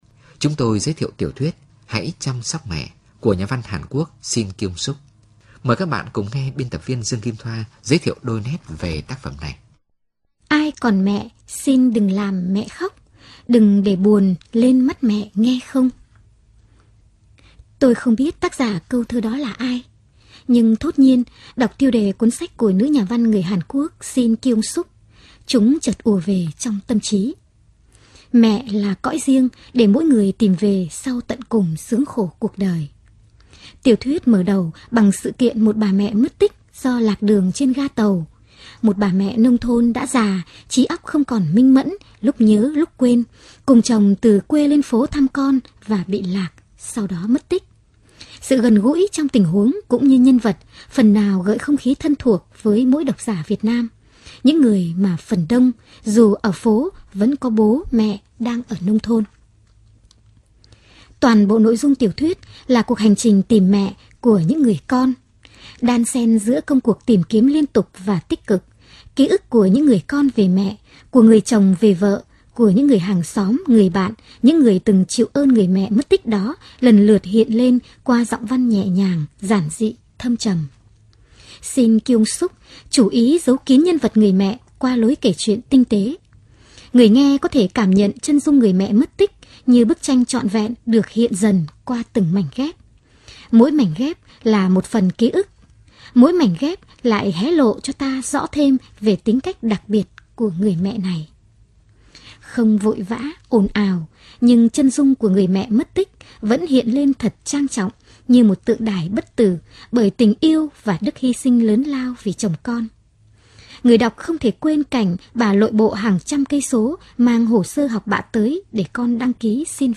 Sách nói | Hãy Chăm Sóc Mẹ